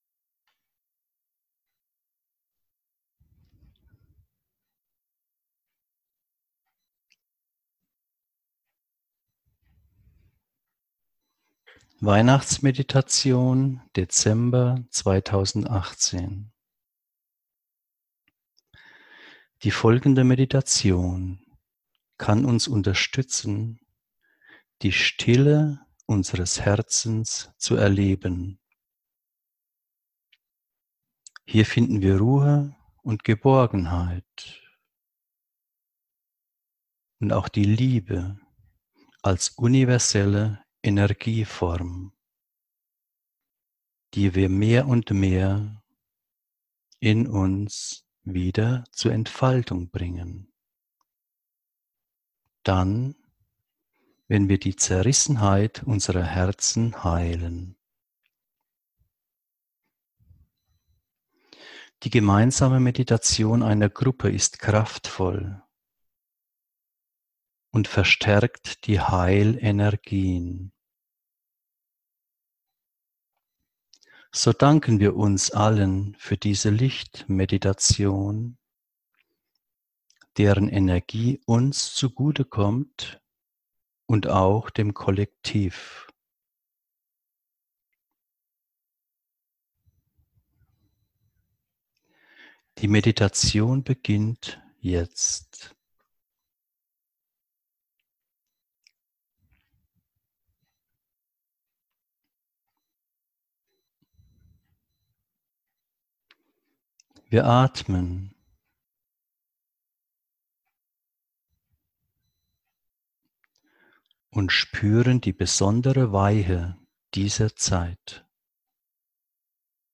Diese geführte Weihnachtsmeditation dient zur Erfahrung der Stille in unseren Herzen. Einleitung: Die folgende Meditation kann uns unterstützen, die Stille unseres Herzens zu erleben.